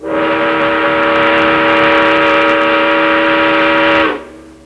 Sons urbanos 35 sons